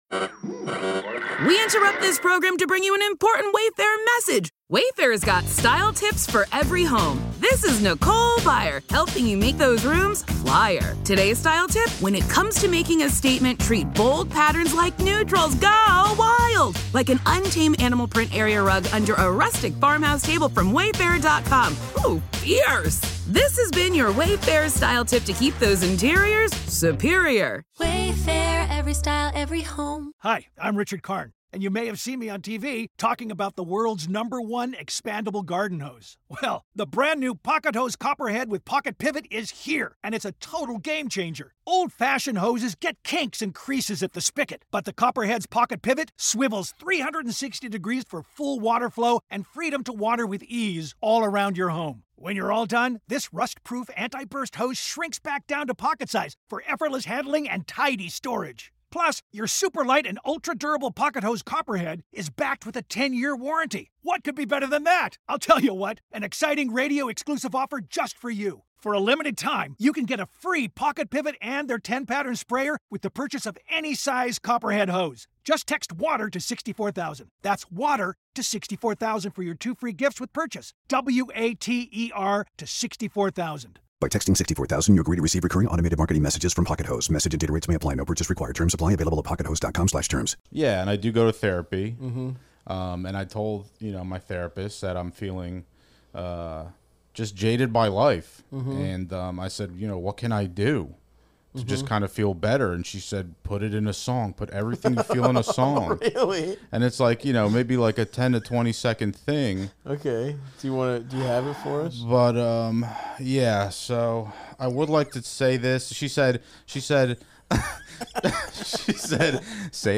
help callers including a guy wondering about texting etiquette with girls off dating apps, and a woman who wants to know how to attract higher caliber men.